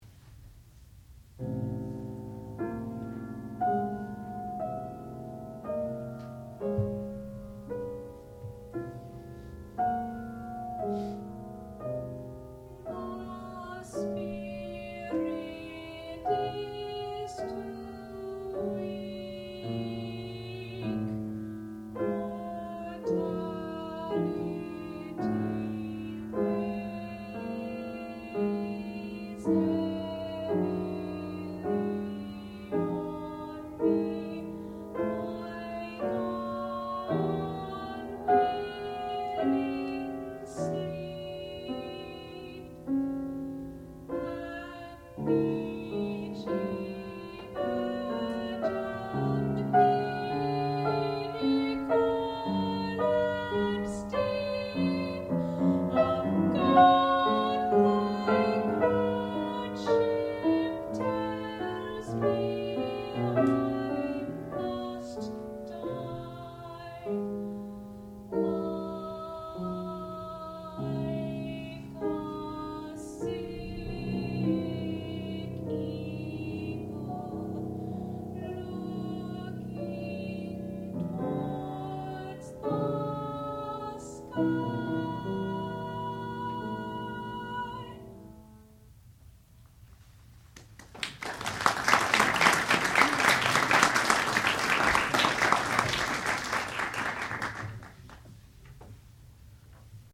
sound recording-musical
classical music
mezzo-soprano
piano
Qualifying Recital